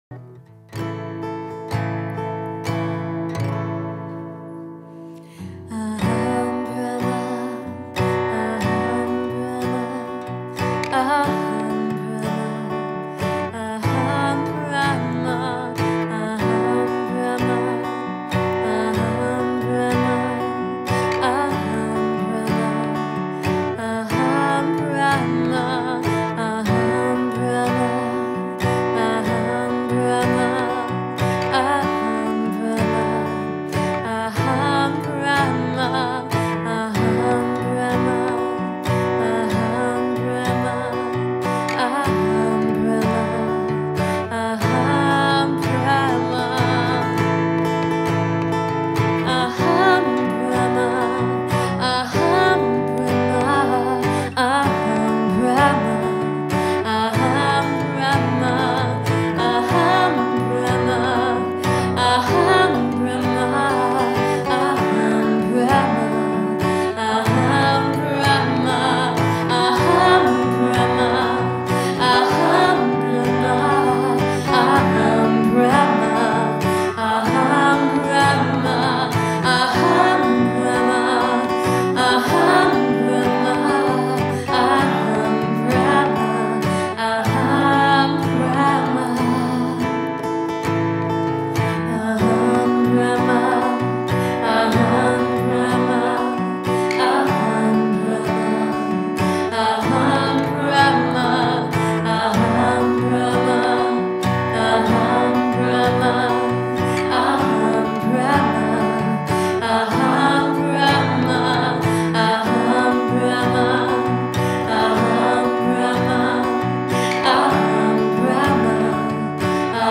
Ein wunderbarer Kirtan